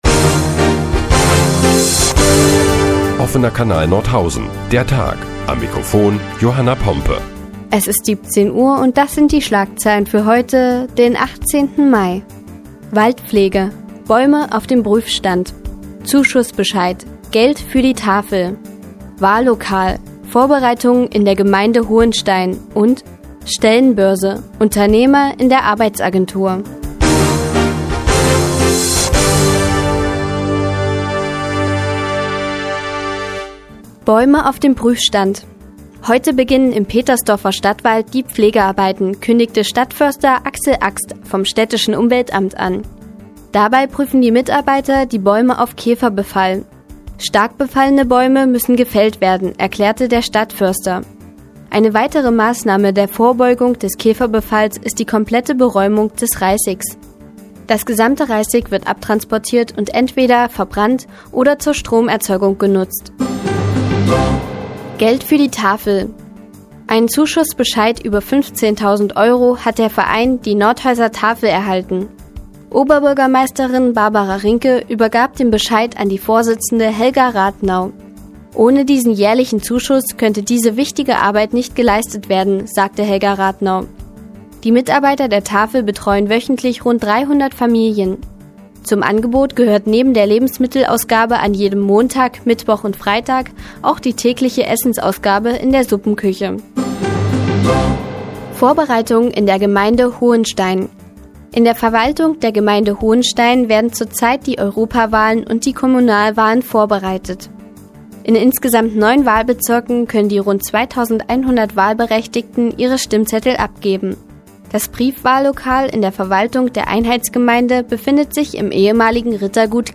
Die tägliche Nachrichtensendung des OKN ist nun auch in der nnz zu hören. Heute geht es unter anderem um Bäume auf dem Prüfstand und Unterstützung für die Tafel.